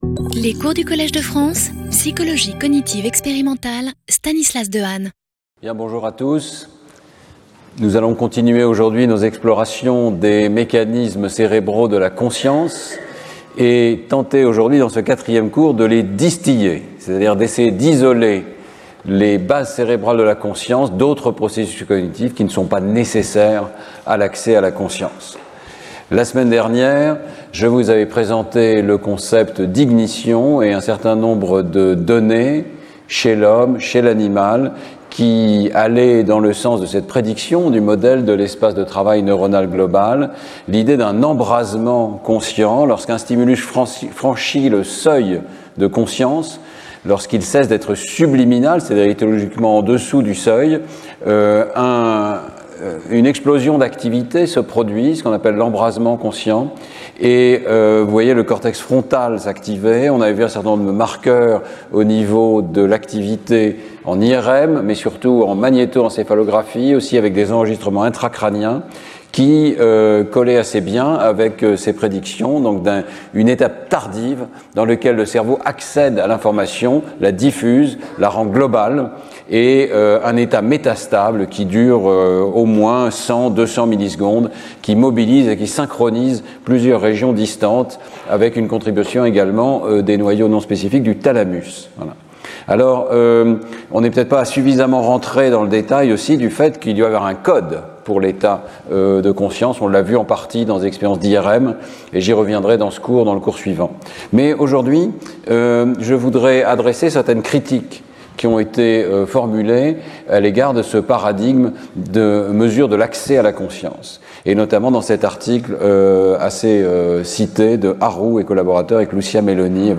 Speaker(s) Stanislas Dehaene Professor at the Collège de France